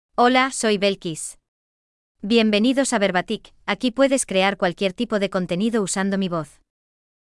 FemaleSpanish (Cuba)
Belkys — Female Spanish AI voice
Belkys is a female AI voice for Spanish (Cuba).
Voice sample
Belkys delivers clear pronunciation with authentic Cuba Spanish intonation, making your content sound professionally produced.